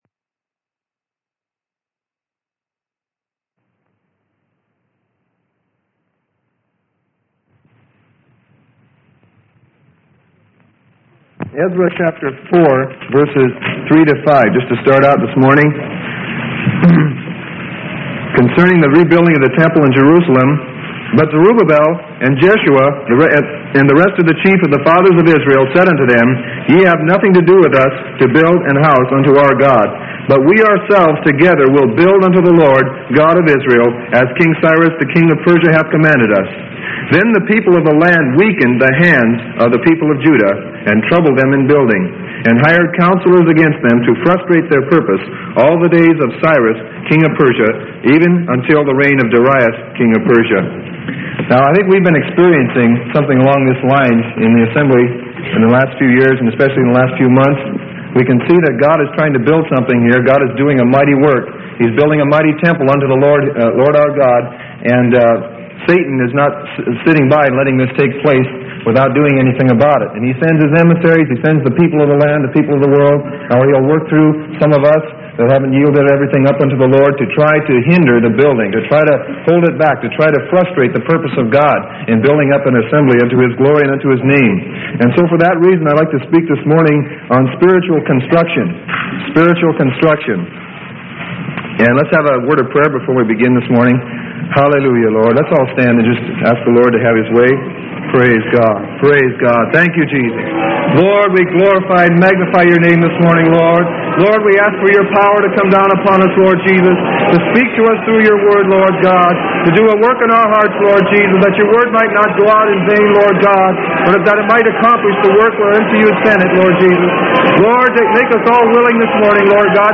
Sermon: Spiritual Building - Freely Given Online Library